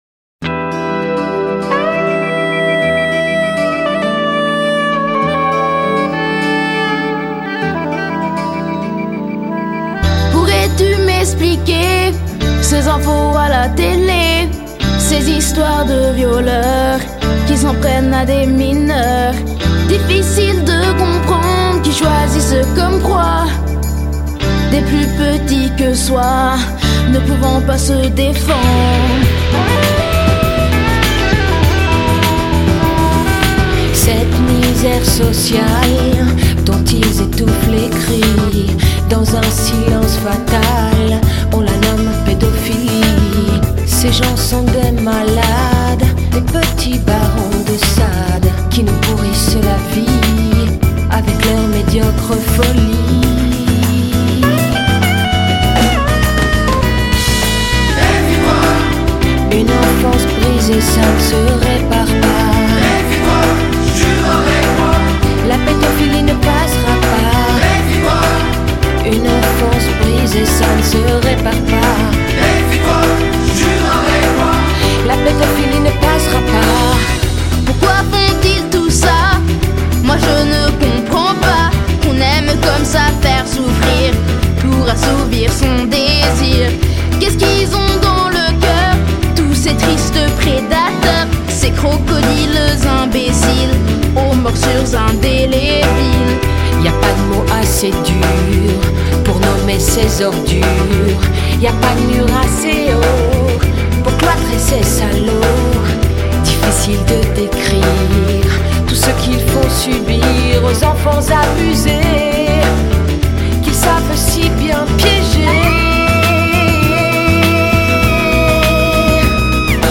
Enregistré au studio Davout